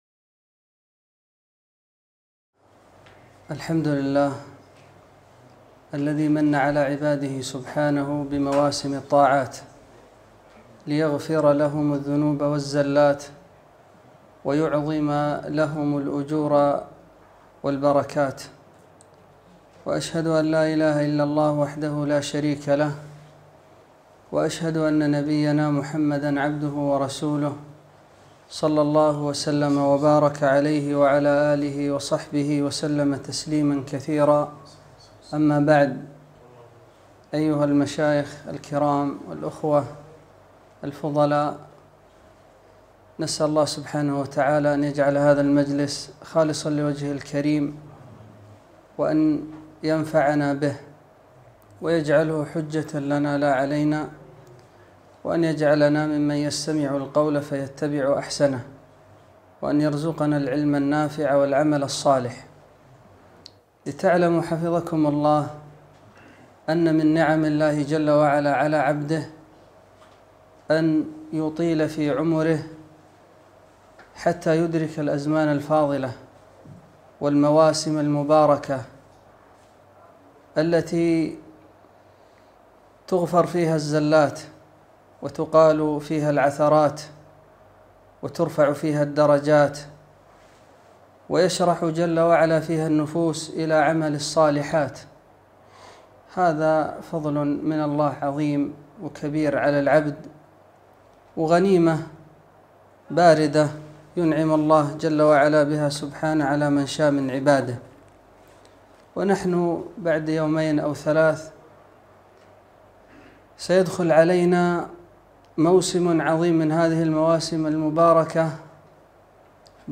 محاضرة - فضائل ووظائف عشر ذي الحجة - دروس الكويت